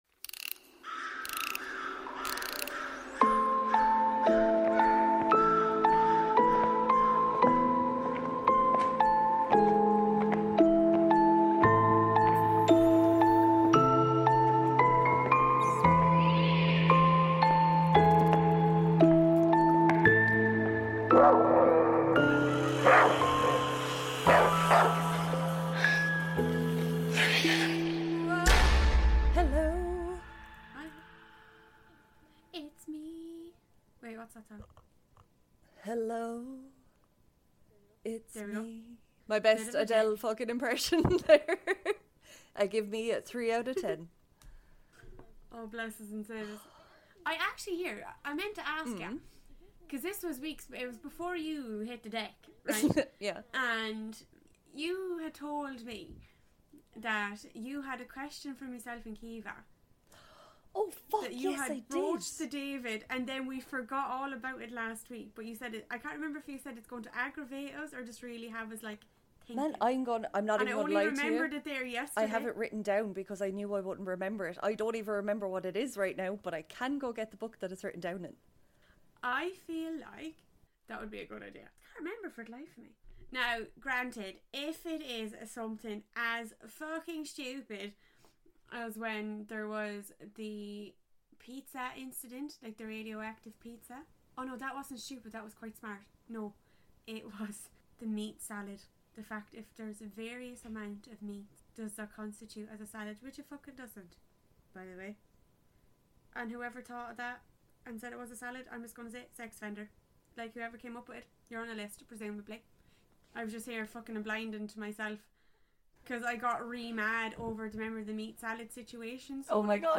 * There is some slight audio issues with this weeks episode.